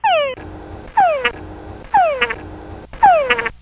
I. Tungara Frog Calls
soiund file of the calls illustrated in the figure above
tungara-coompl-series-3.mp3